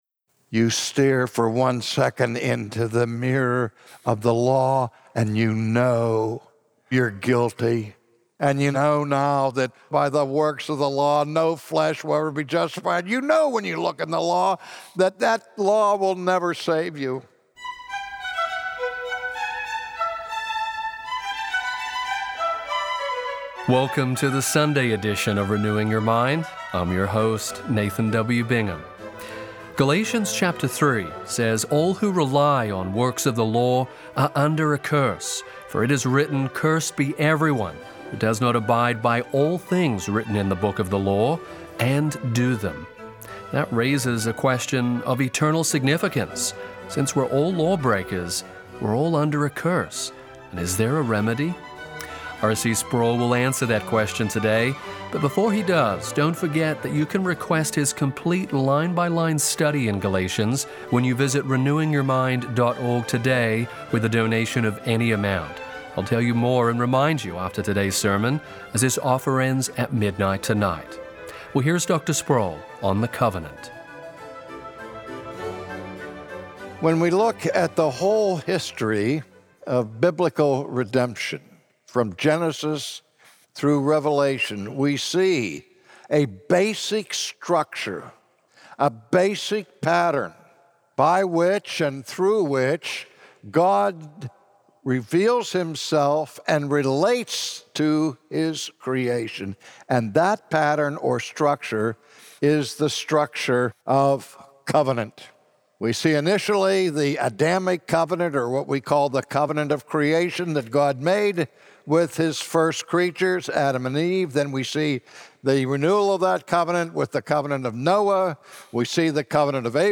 From his sermon series in the book of Galatians